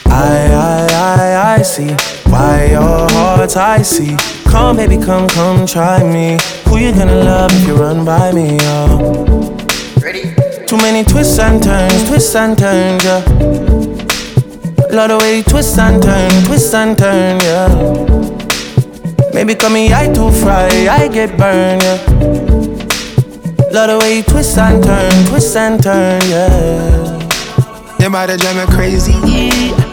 • Reggae